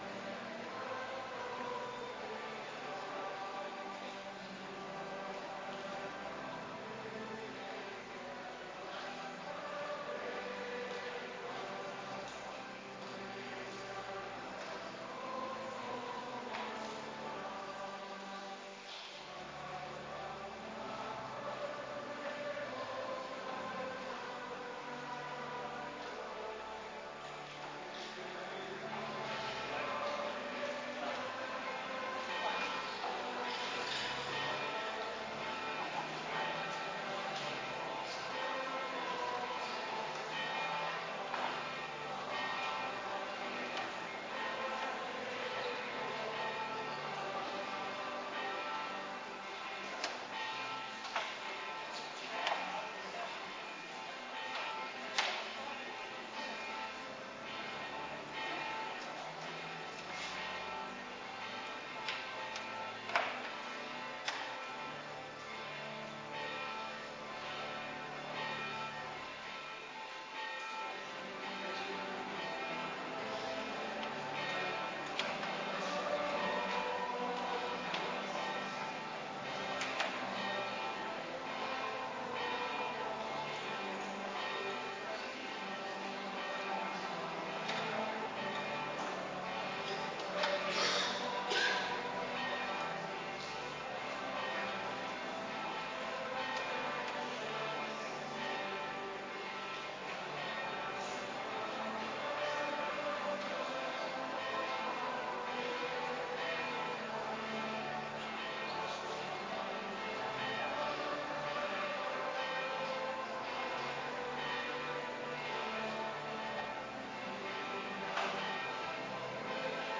Adventkerk Zondag week 30